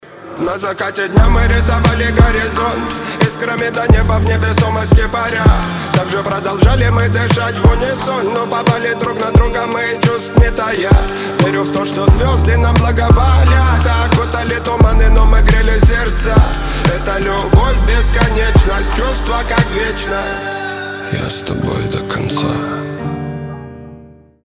Романтические рингтоны
Рэп рингтоны